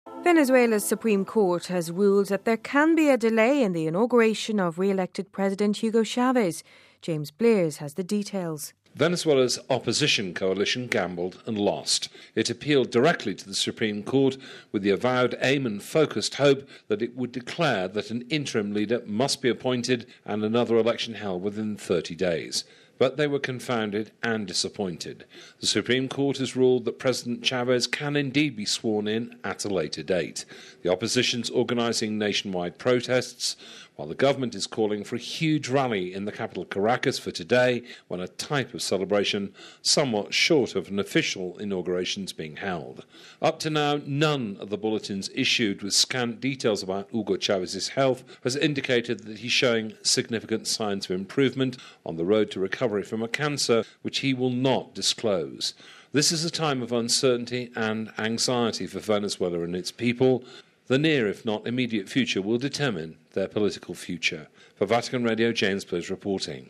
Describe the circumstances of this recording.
(Vatican Radio) Venezuela's Supreme Court has ruled that there can indeed be a delay in the Inauguration of re-elected President Hugo Chavez..Venezuela's oppostion coalition gambled and lost.